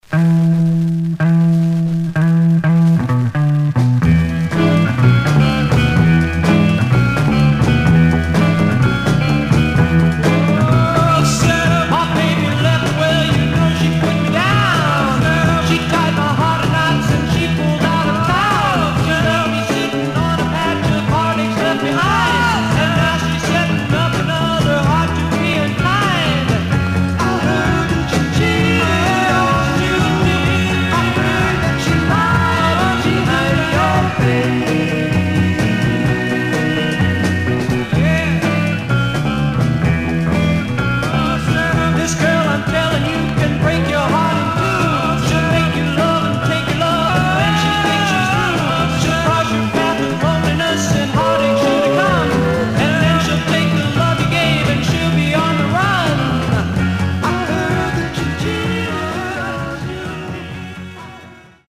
Surface noise/wear Stereo/mono Mono
Garage, 60's Punk